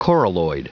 Prononciation du mot coralloid en anglais (fichier audio)
Prononciation du mot : coralloid